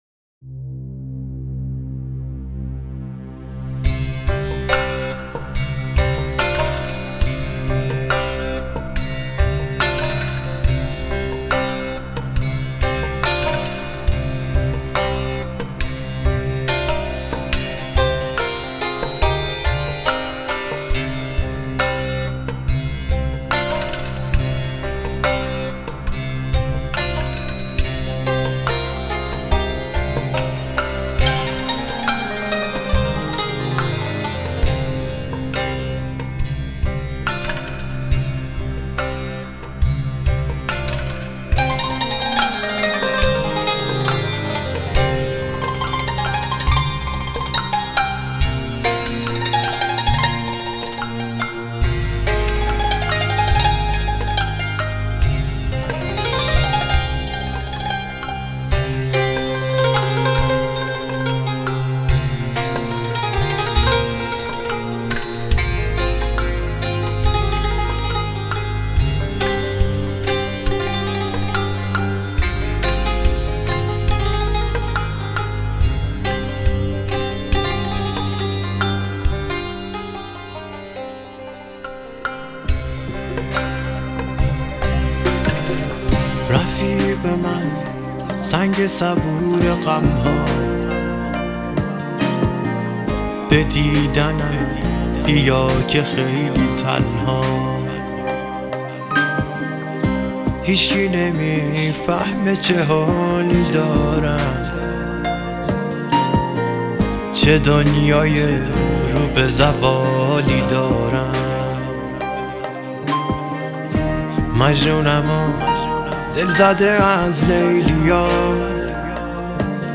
آهنگ جاودان و احساسی
با صدای ملکوتی